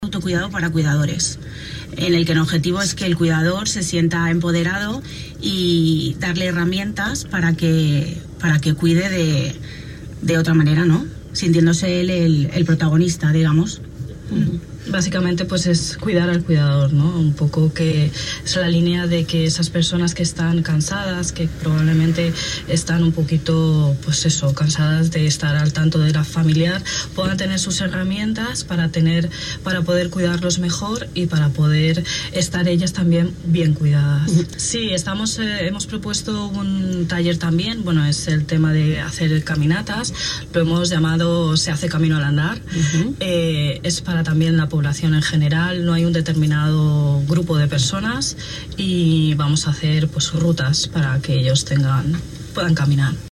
Entrevistas Sanidad